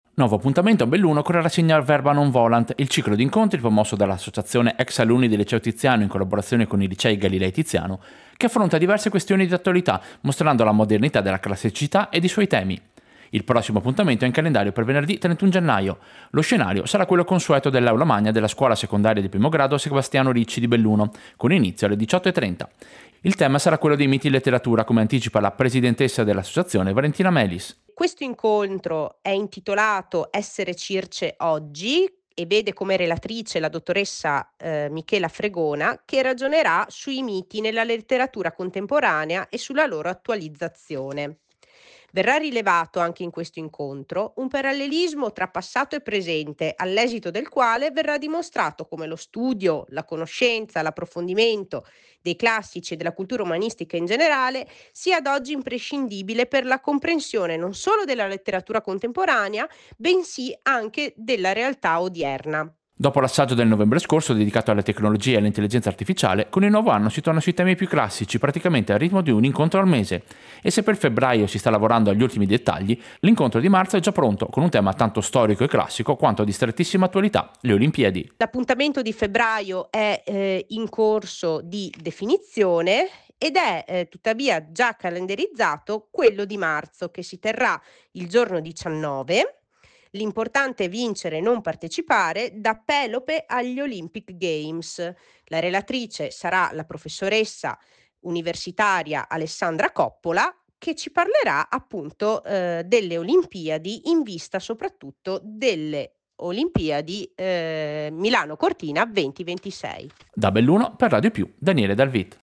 Servizio-Essere-Circe-oggi.mp3